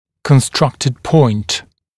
[kən’strʌktɪd pɔɪnt][кэн’страктид пойнт]воображаемая точка, построенная точка (отосительно каких-либо ориентиров)